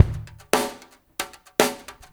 SPL BR DRM-R.wav